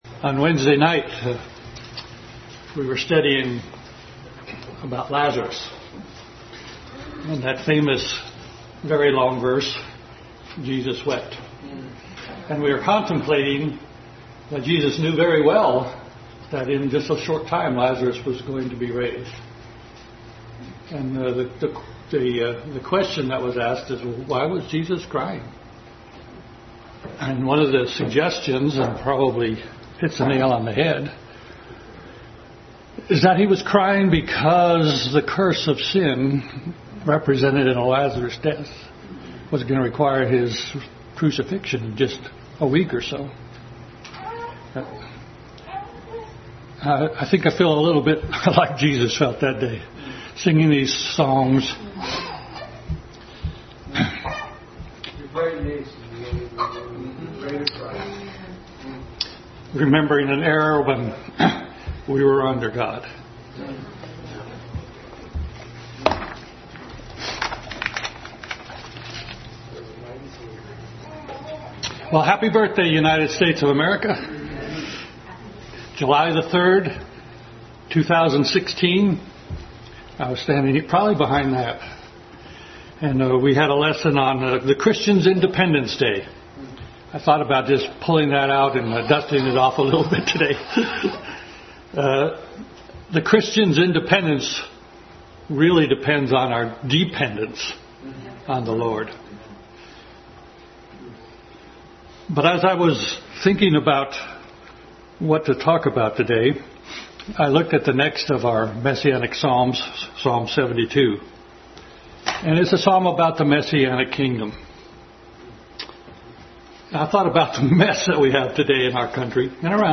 Family Bible Hour Message.
Psalm 72 Passage: Psalm 72:1-20 Service Type: Family Bible Hour Family Bible Hour Message.